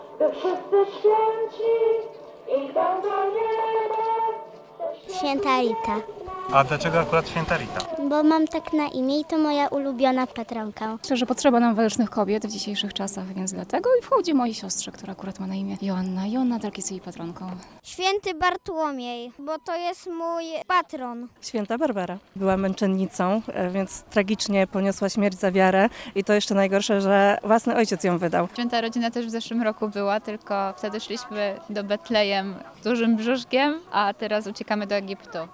Pokazali to uczestnicy Marszu Świętych, organizowanego już po raz trzeci w parafii Świętej Rity na warszawskiej Pradze.